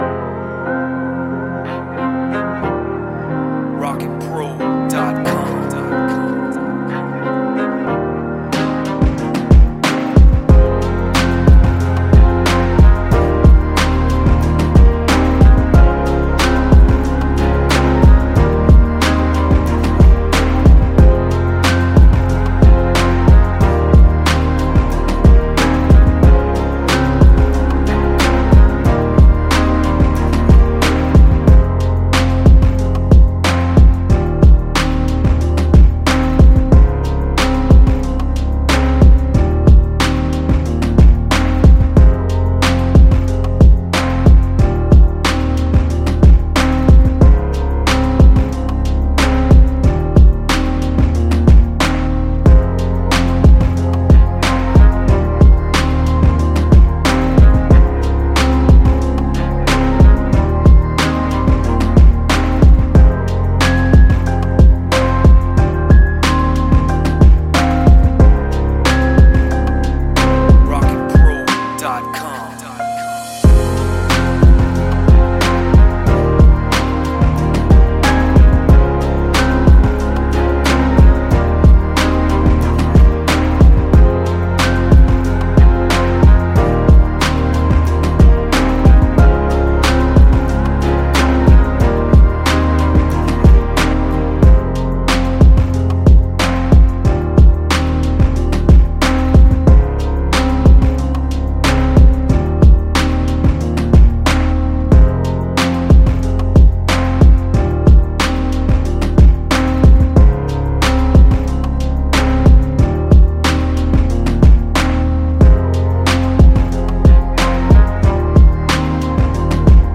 Sad lofi type beat with piano chords and pads.
97 BPM.